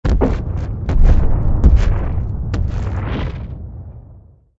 SA_tremor.ogg